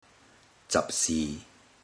Click each Romanised Teochew word to listen to how the Teochew word is pronounced.
zab10si5